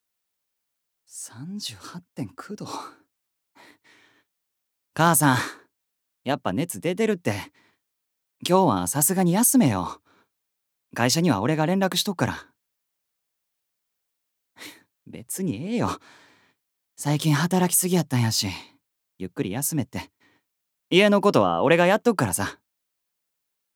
Voice Sample
セリフ３